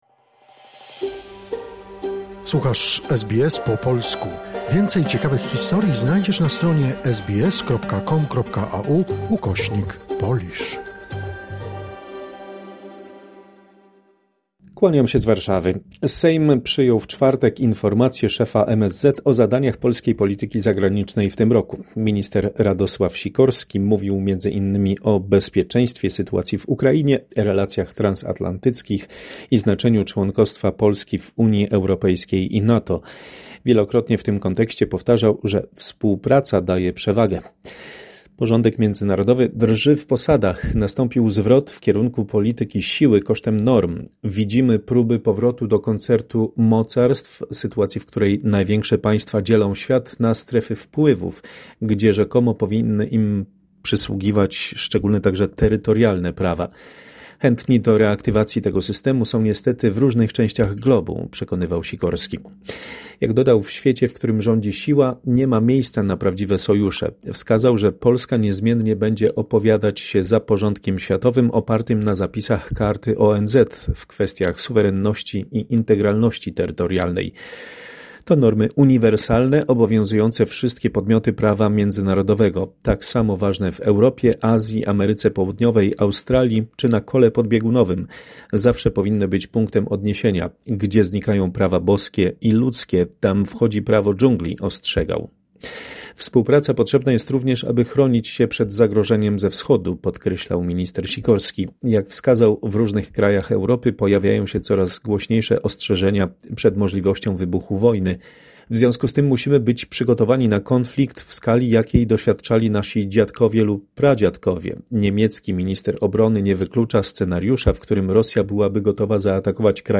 W korespondencji z Polski: polityka międzynarodowa polskiego rządu i wprowadzenie przez Episkopat Polski kar finansowych dla duchownych.